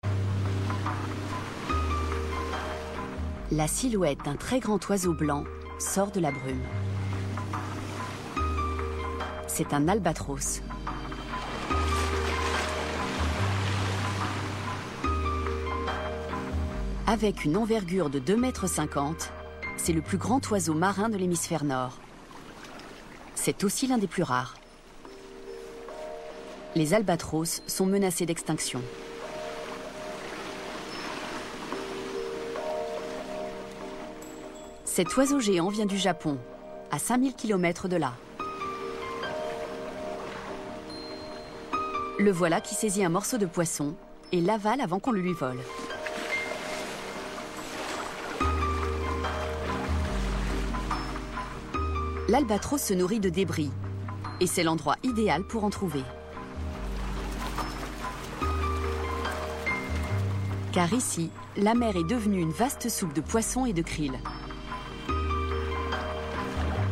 Narration : Un festin aux îles Aléoutiennes